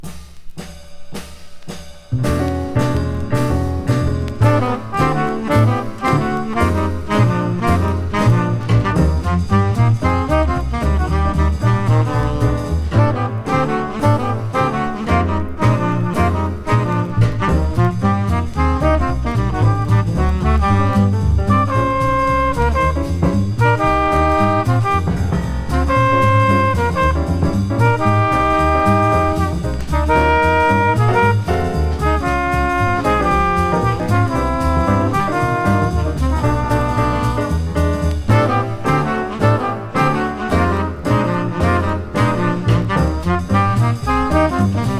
Jazz, Cool Jazz　USA　12inchレコード　33rpm　Mono